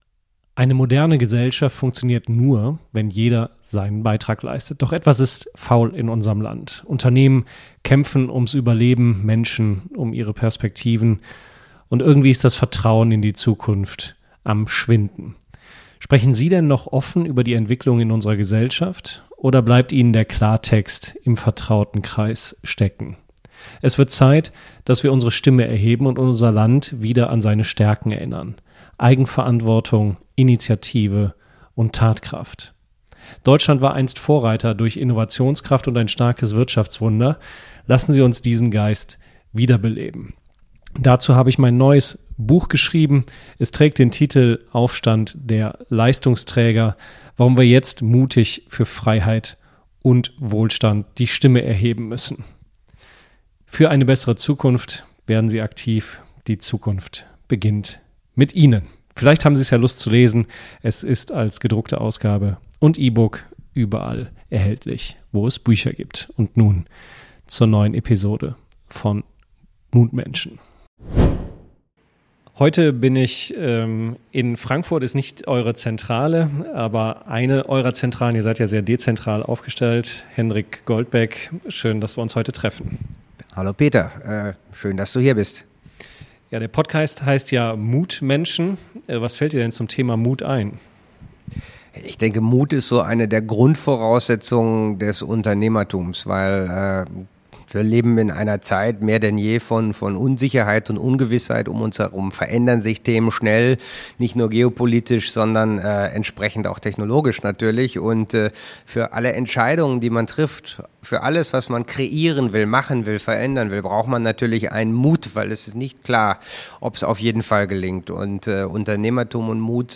Wir tauchen ein in Themen wie Mut im Unternehmertum, die Kraft klarer Werte, Leistung als Haltung statt Floskel, Verantwortung für Mitarbeiter und Standort, und die Frage, wie Deutschland wieder in den Zukunftsmodus kommt. Es geht um Tempo statt Zaudern, konstruktive Streitkultur statt Meckern, echte Zusammenarbeit und den Willen, Dinge zu bauen statt zu beklagen. Ein Gespräch mit Blick nach vorn.